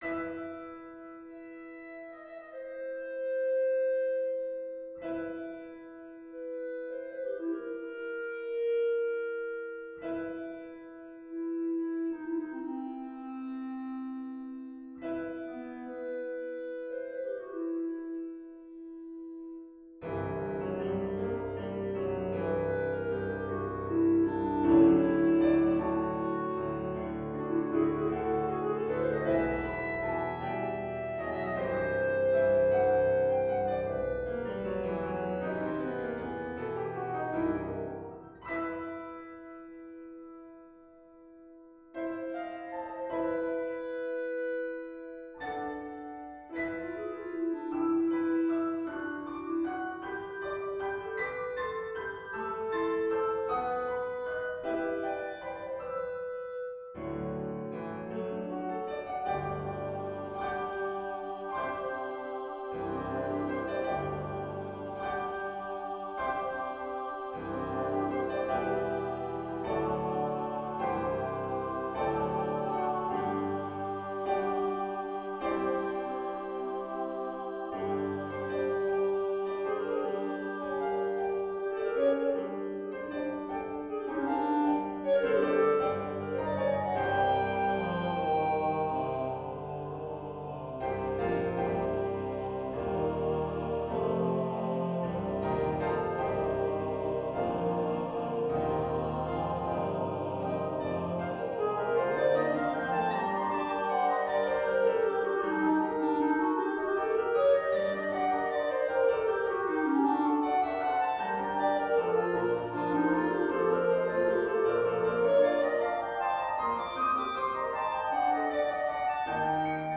SATB Choir, Clarinet in A, and Piano
We-Three-Kings-Clarinet-Finale-Audio-File.mp3